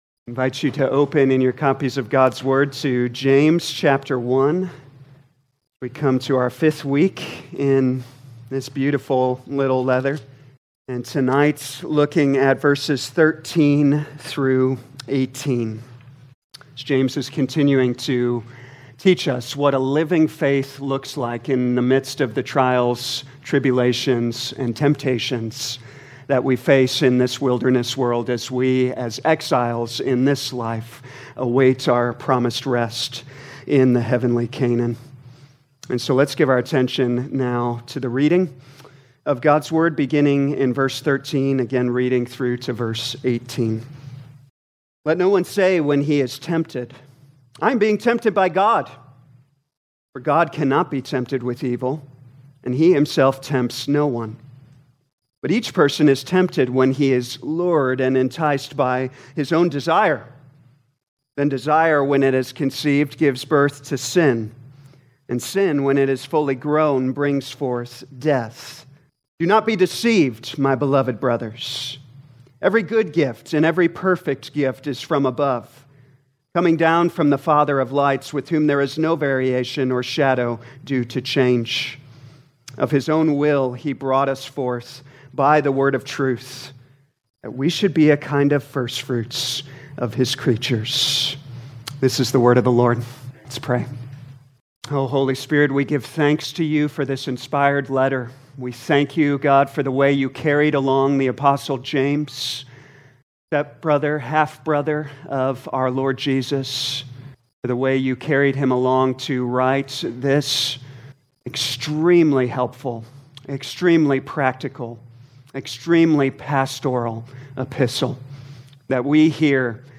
2025 James Evening Service Download